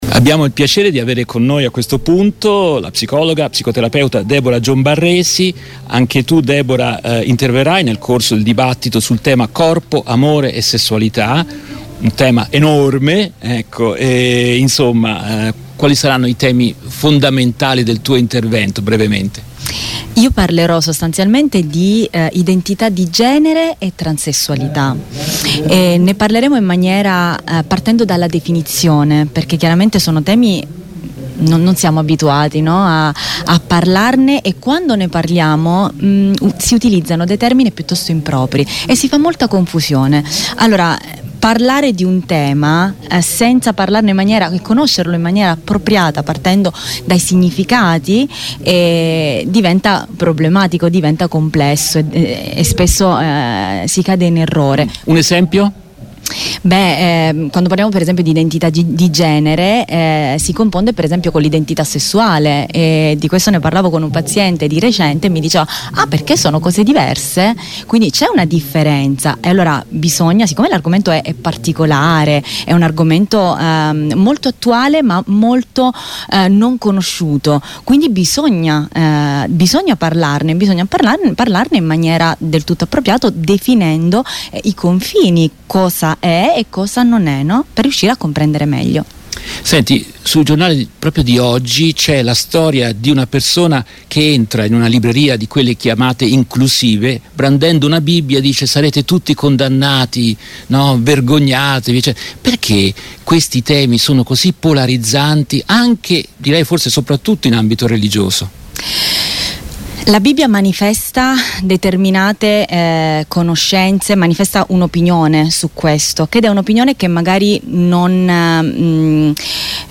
Voci dal Villa Aurora Meeting 2023